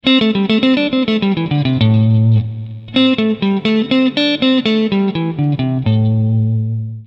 Fraseggio blues 09
Meno usuale, ma non per questo meno interessante, l'utilizzo del box 1 rapportato all'uso delle dita come indicato.